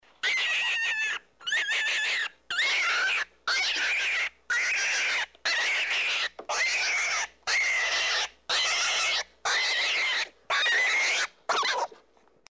3. Кролик вопит